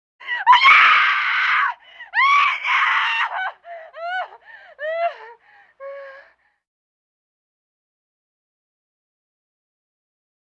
3-67-female-screaming-in-terror.mp3